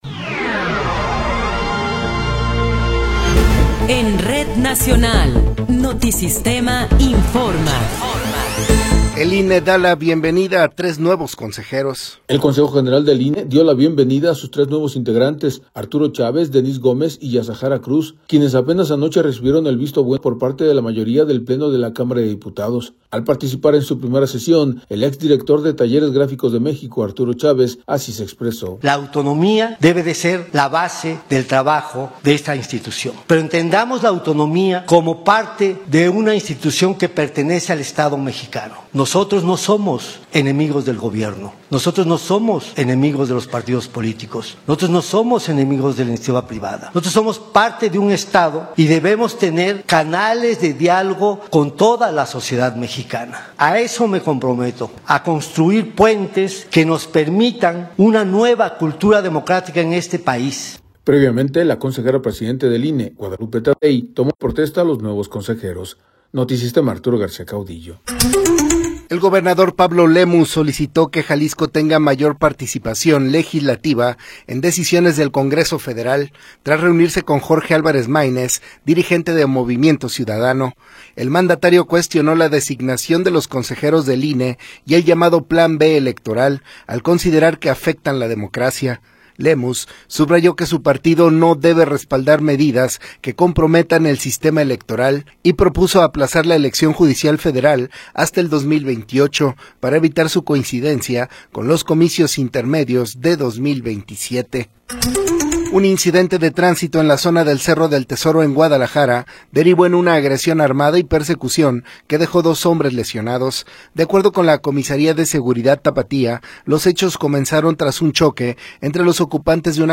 Noticiero 18 hrs. – 22 de Abril de 2026